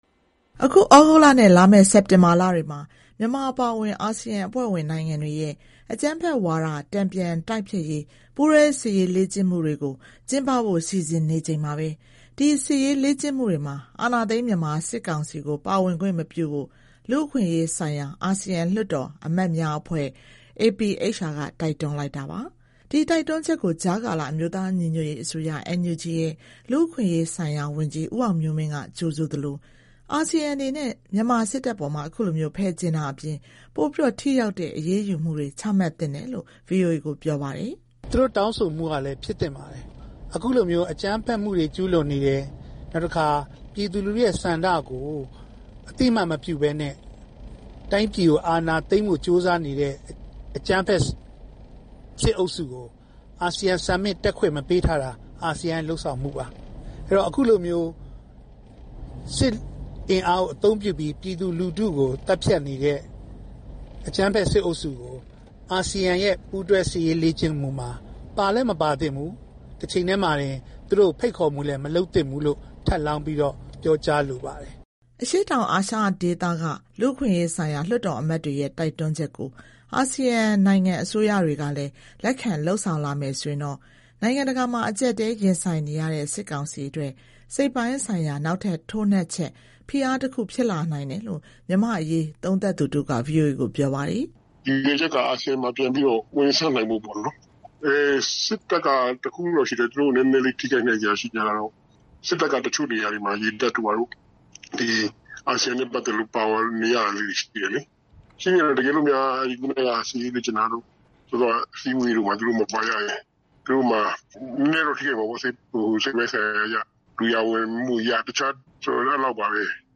အာဆီယံအသင်းဝင် နိုင်ငံတွေရဲ့ အကြမ်းဖက်ဝါဒ တန်ပြန်တိုက်ဖျက်ရေး ပူးတွဲစစ်ရေး လေ့ကျင့်မှုတွေမှာ စစ်ကောင်စီကို ဖယ်ကြဉ်ထားဖို့ လူ့အခွင့်အရေးဆိုင်ရာ အာဆီယံ လွှတ်တော်အမတ်များ အဖွဲ့ APHR က ပြီးခဲ့တဲ့ ရက်ပိုင်းက တိုက်တွန်းလိုက်ပါတယ်။ နိုင်ငံတကာရဲ့ ပိတ်ဆို့ ဖယ်ကြဥ်မှုတွေ နဲ့ အကျပ်အတည်းတွေ ရင်ဆိုင်နေရတဲ့ စစ်ကောင်စီကို အာဆီယံအနေနဲ့ ထပ်တိုး ဖယ်ကြဥ်ဖို့ ခုလို တောင်းဆိုတာတွေက စစ်ကောင်စီအပေါ် ဘယ်လို ဖိအားတွေ ဖြစ်လာစေနိုင်ပါလဲ။ ရန်ကုန်က ပေးပို့တဲ့သတင်းကို တင်ပြပေးပါမယ်။